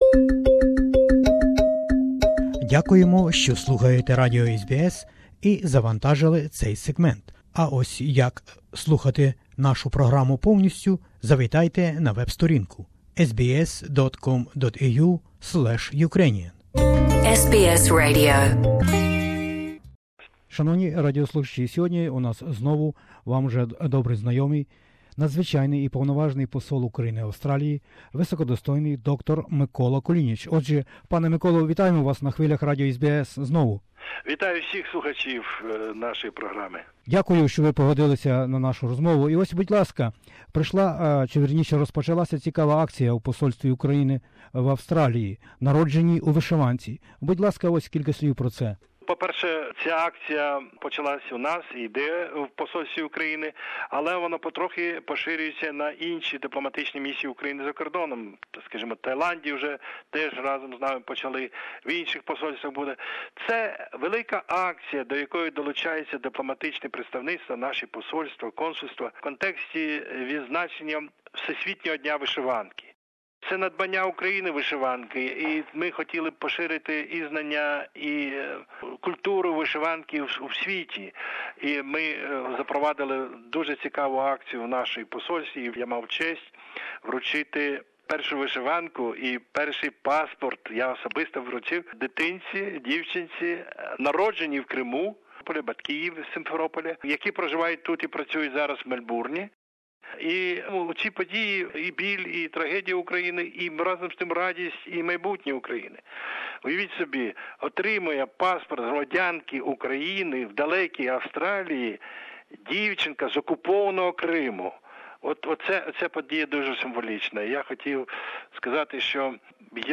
interviewed the Ambassador of Ukraine Dr Mykola Kulinich. We spoke about the first baby, who was registered as a citizen of Ukraine in the distant Australia.